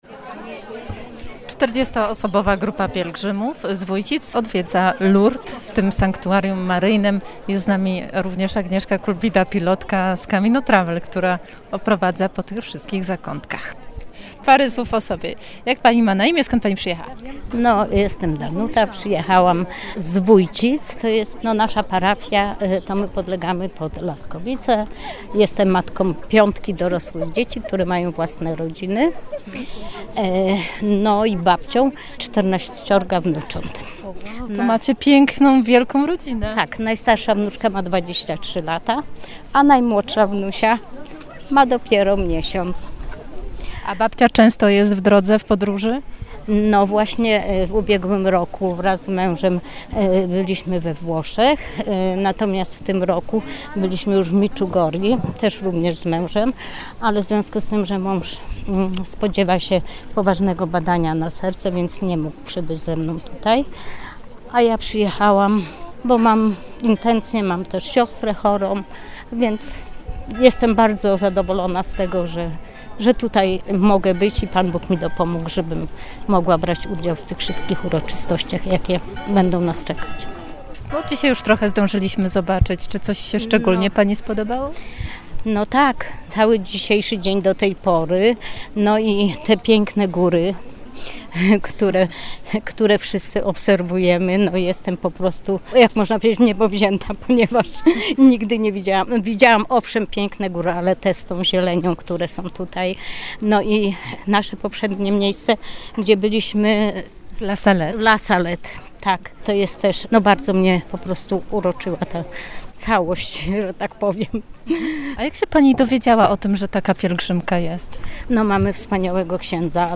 Przypominamy migawkę z pobytu w Lourdes razem z grupą pielgrzymów z parafii z Wójcic, którą odbyliśmy we wrześniu ub. roku.